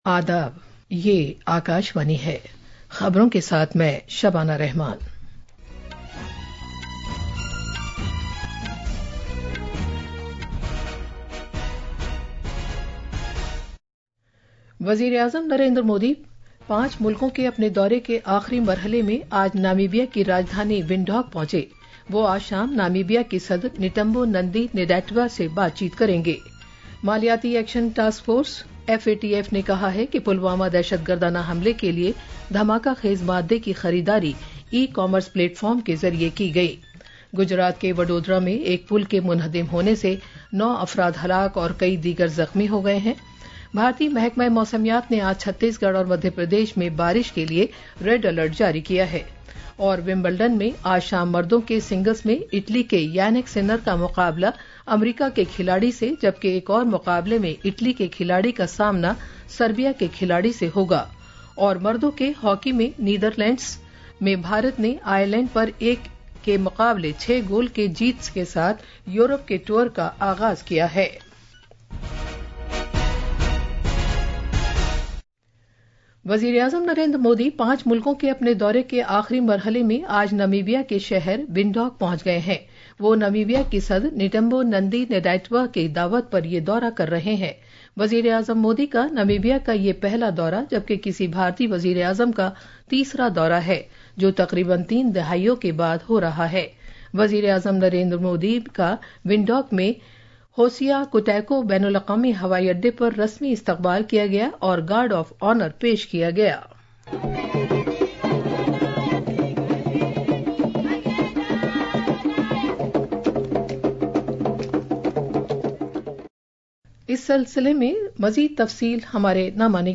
Midday News